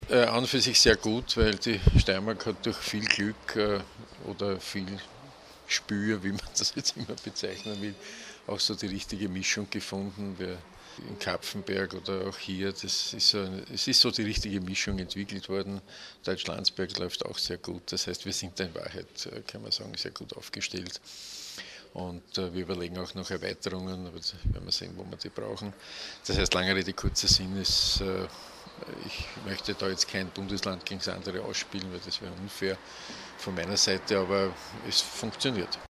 Zwei Jahre steirische Produktionsschulen - O-Töne
Bundesminister Rudolf Hundstorfer: